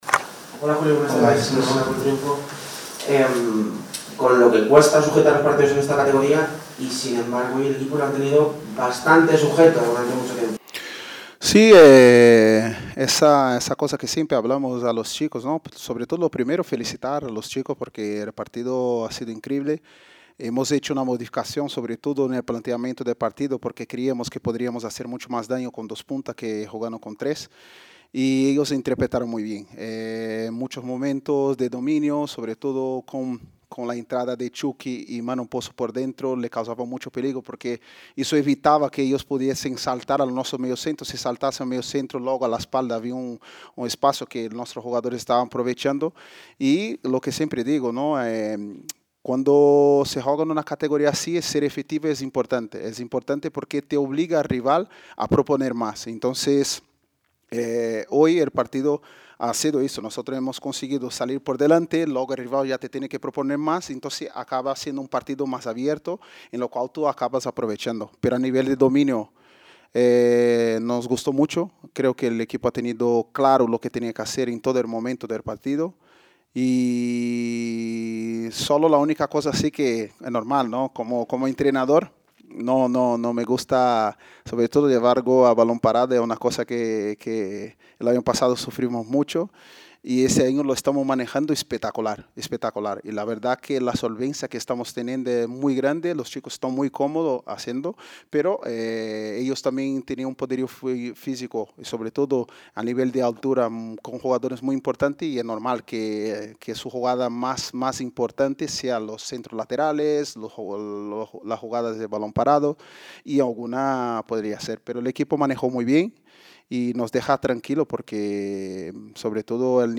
Así comenzó Julio Baptista la rueda de prensa tras la victoria (4-1) del Real Valladolid Promesas ante el Marino de Luanco, contra quien cambiaron el esquema con buen resultado.